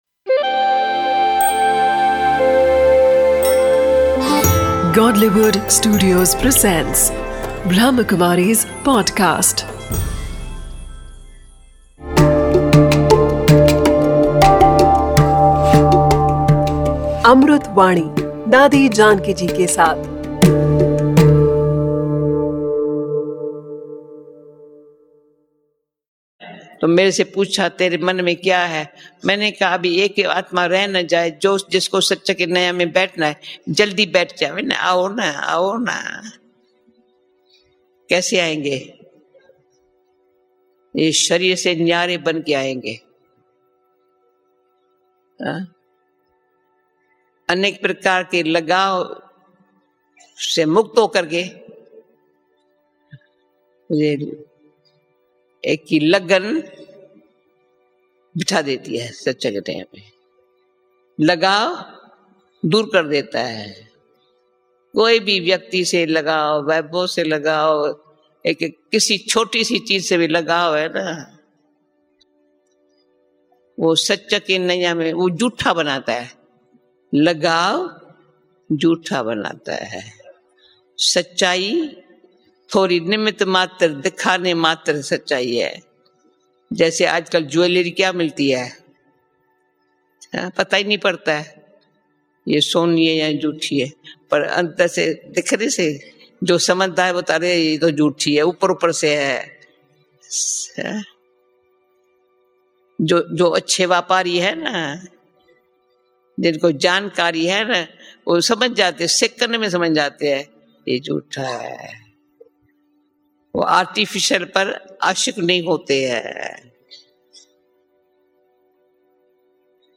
'Amrut Vani' is a collection of invaluable speeches